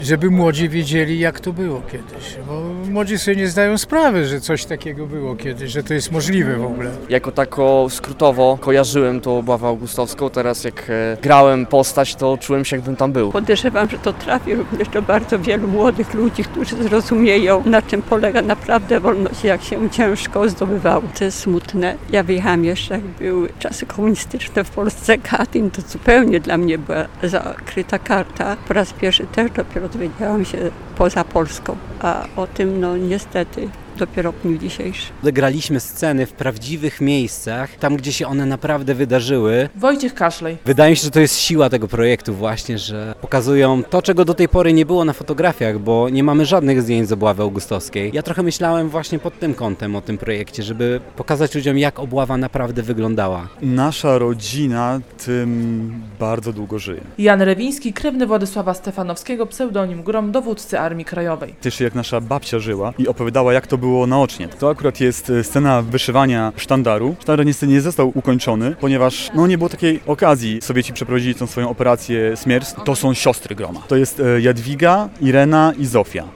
Wystawa, ukazująca sceny Obławy Augustowskiej, w centrum Augustowa - relacja